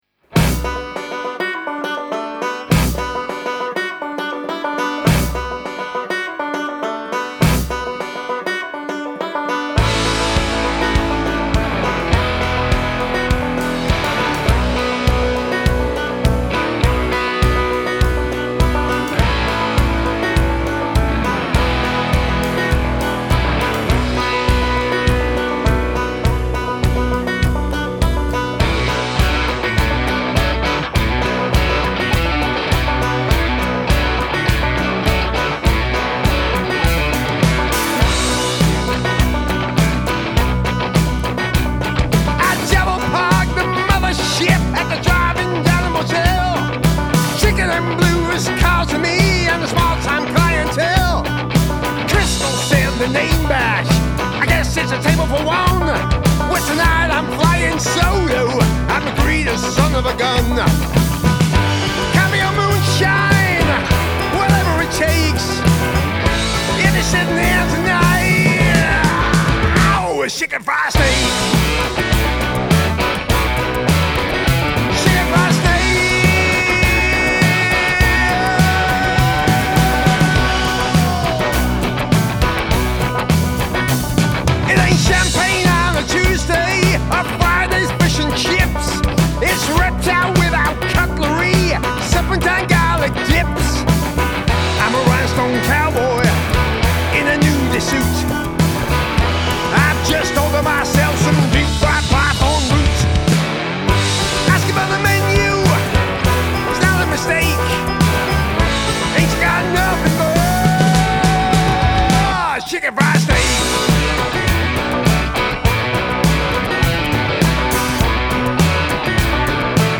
Male Vocal, Guitar, Banjo, Bass Guitar, Drums